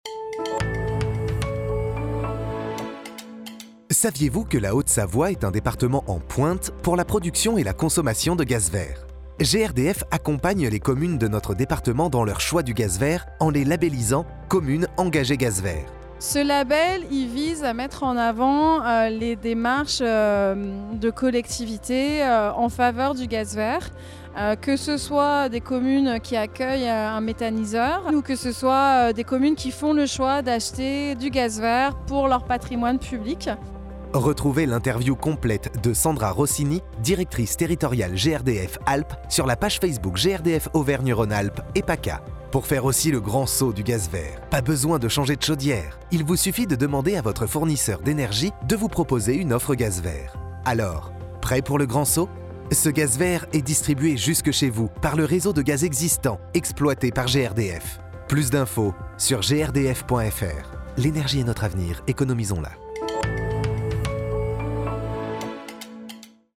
Le 11 octobre dernier, au congrès des maires de Haute-Savoie, ils se sont réunis autour du micro de Studio Métha, la fréquence des acteurs engagés gaz vert.